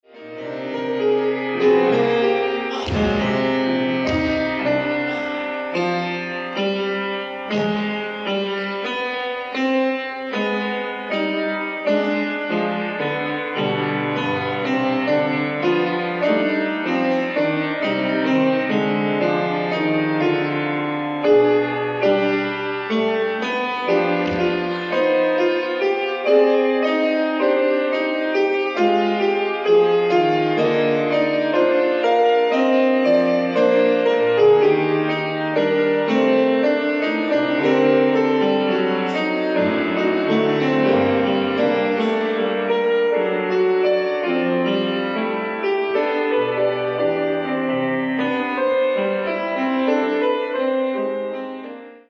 ※試聴用に実際より音質を落としています。